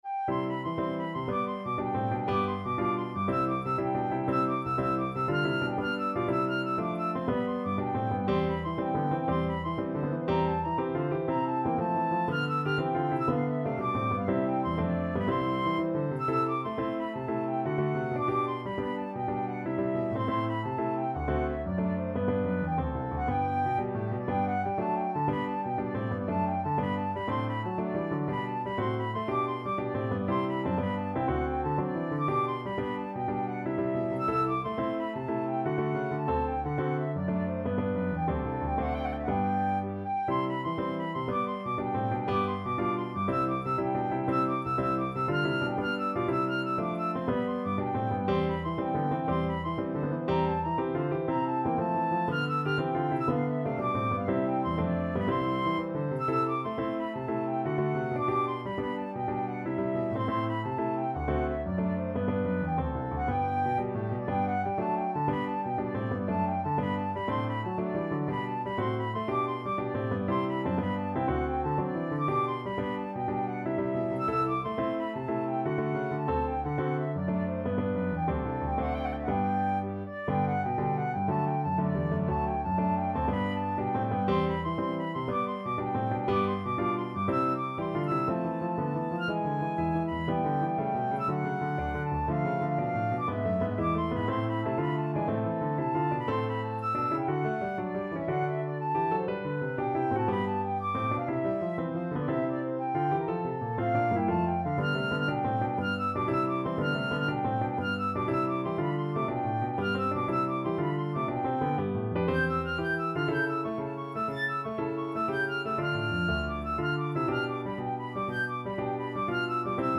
Flute
C major (Sounding Pitch) (View more C major Music for Flute )
IV: Allegro (View more music marked Allegro)
G5-A7
4/4 (View more 4/4 Music)
Classical (View more Classical Flute Music)